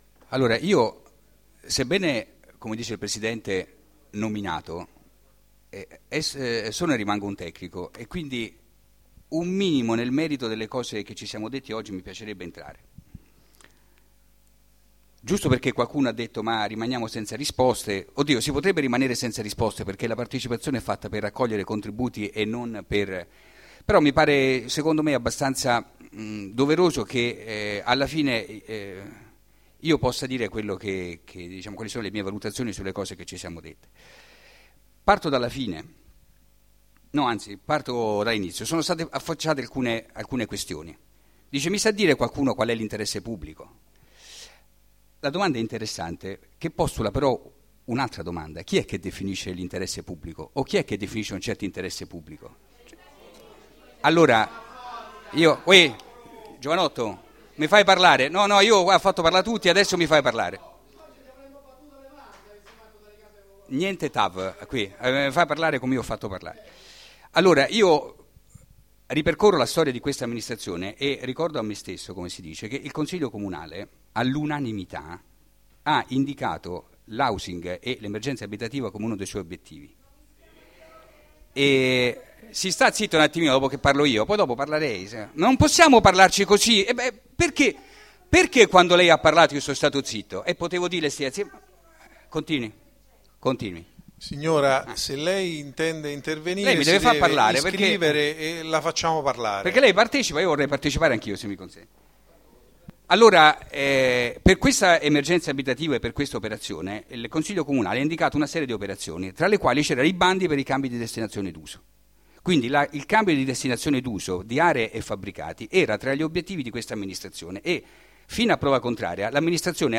Marco Corsini Marco Corsini, Assessore all'Urbanistica